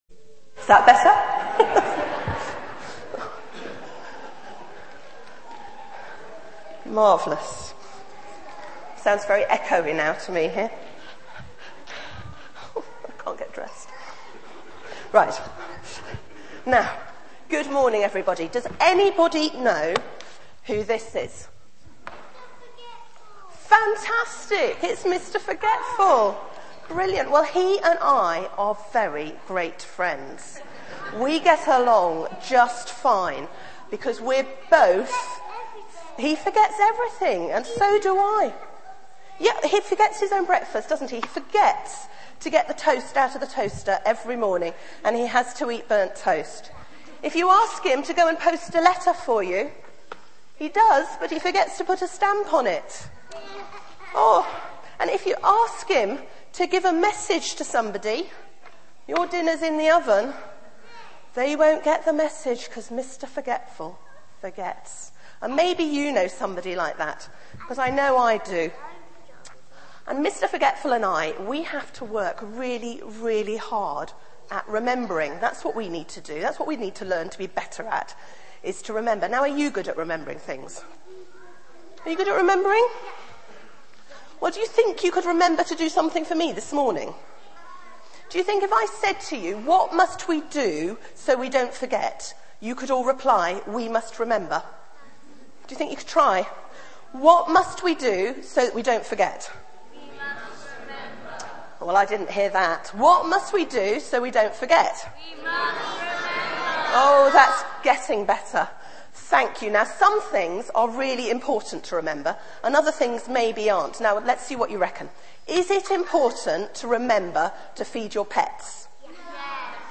Media for 9:15am Service on Sun 14th Nov 2010 09:15 Speaker
Remember Sermon Search the media library There are recordings here going back several years.